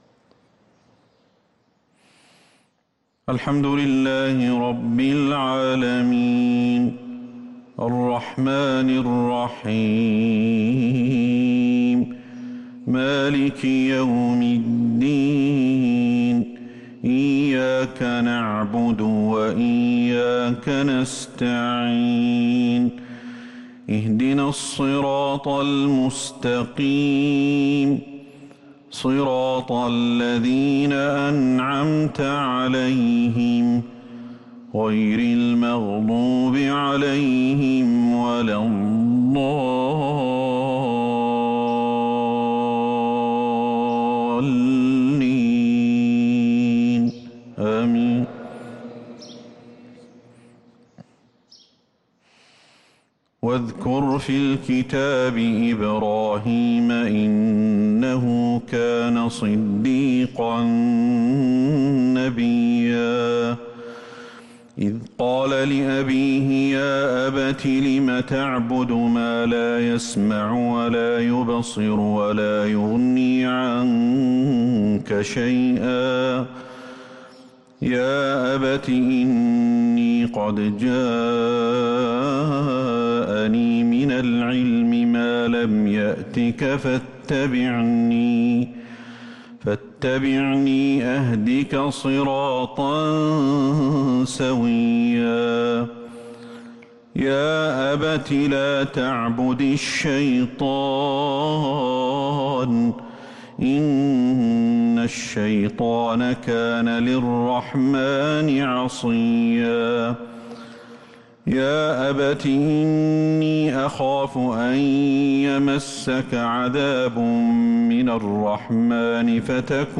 فجر الاثنين 4-8-1443هـ من سورة مريم | Fajr prayer from Surat Maryam 7-3-2022 > 1443 🕌 > الفروض - تلاوات الحرمين